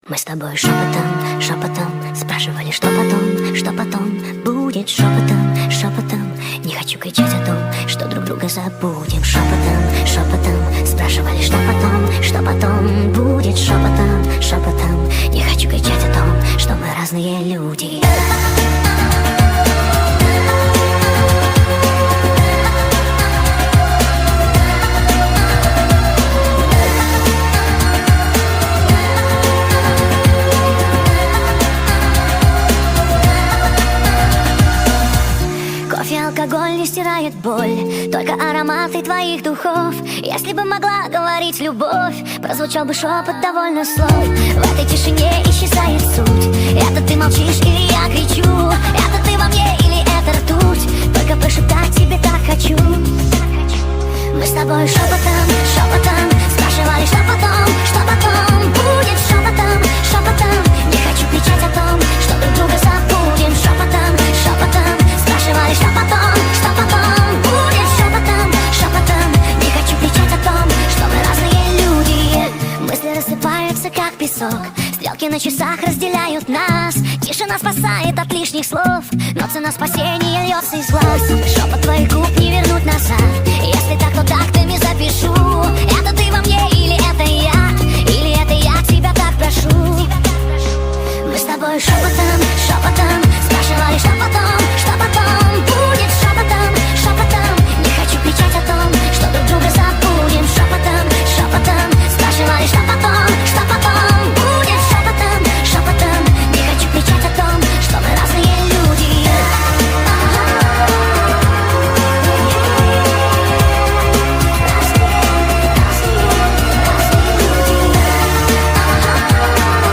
Speed Up TikTok Remix Version